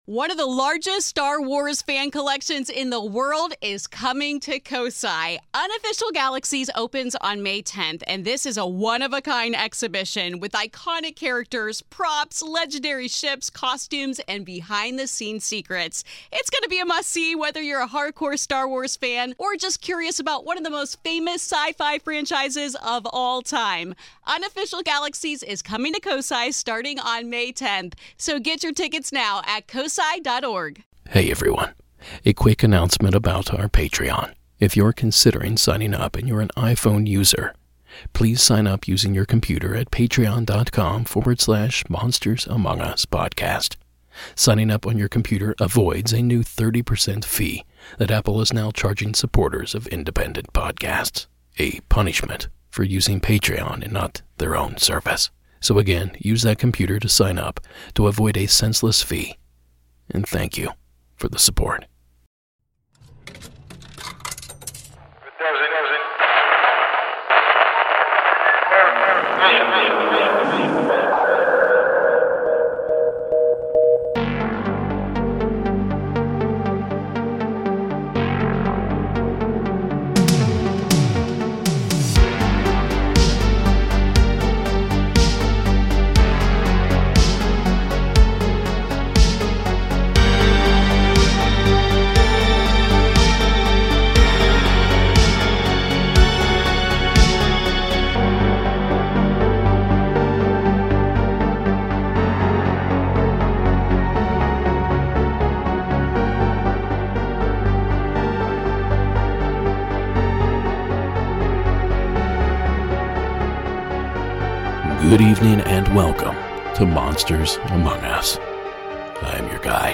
Tonight we hear a call that proves that's not exactly true. Plus, portals, monsters and more!
Season 18 Episode 13 of Monsters Among Us Podcast, true paranormal stories of ghosts, cryptids, UFOs and more, told by the witnesses themselves.